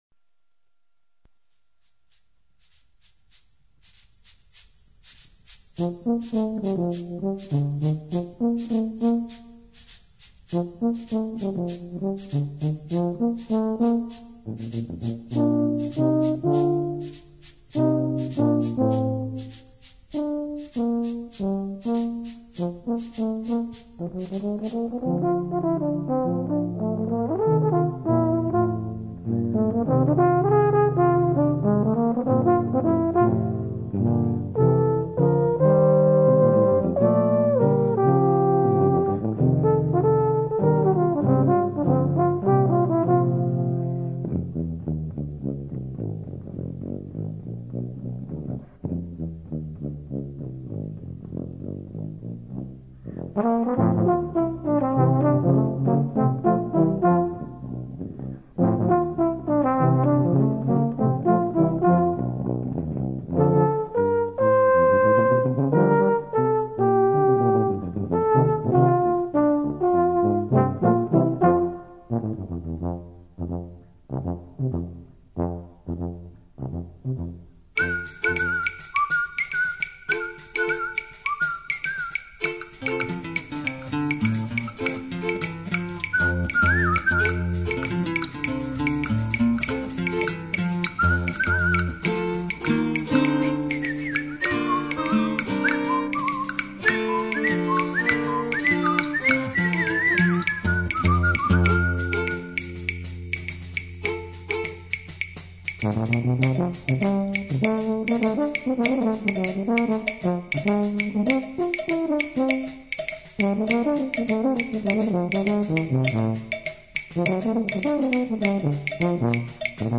For Tuba Quartet (EETT), Composed by Traditional.
opt. vocal parts.